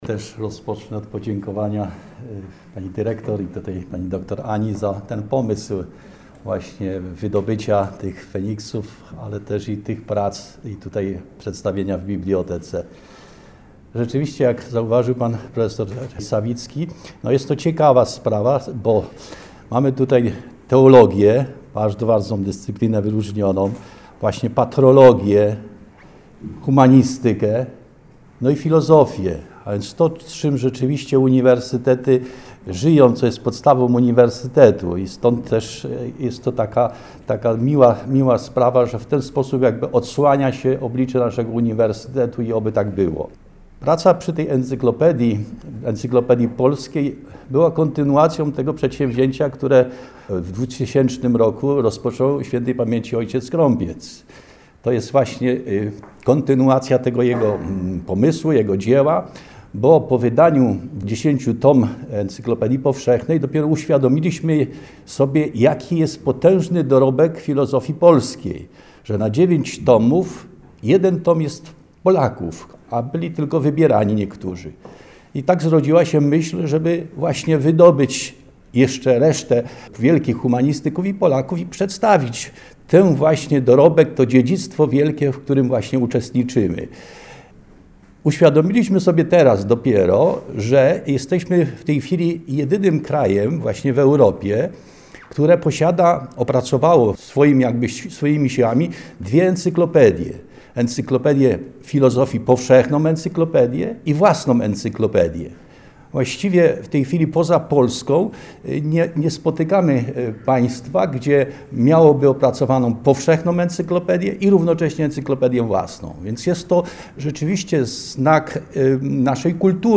Wystąpienia laureatów